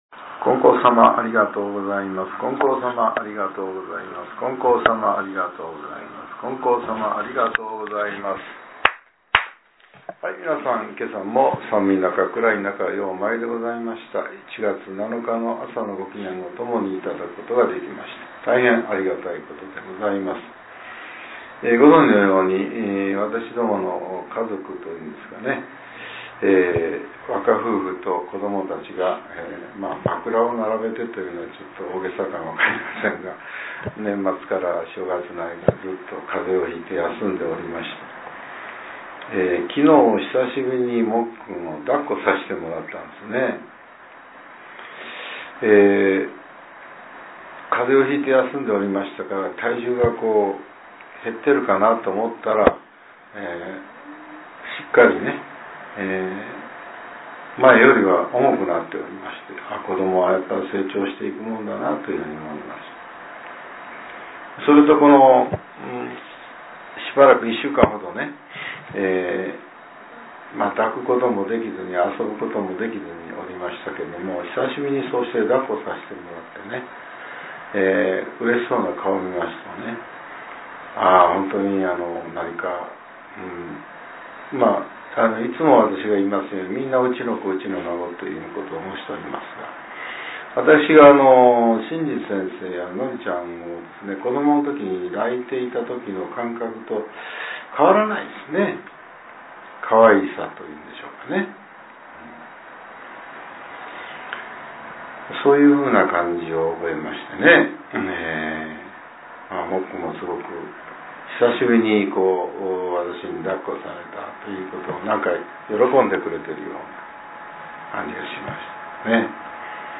令和７年１月７日（朝）のお話が、音声ブログとして更新されています。